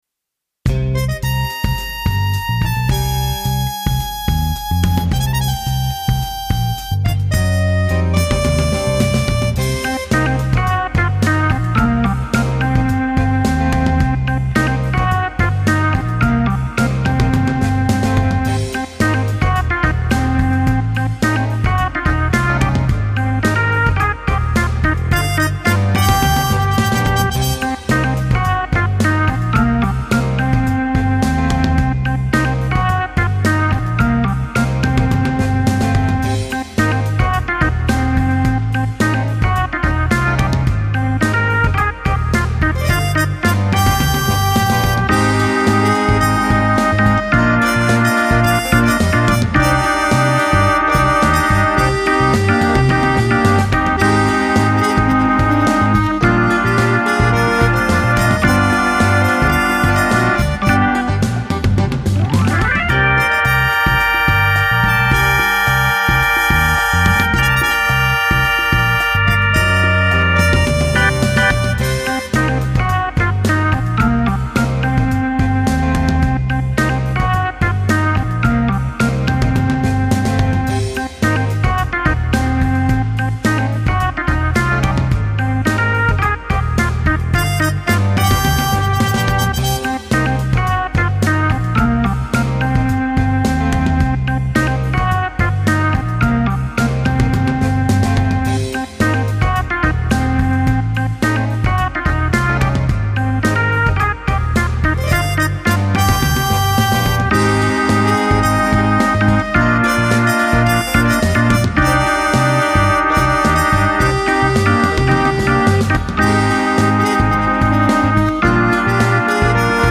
なっつかしーBGM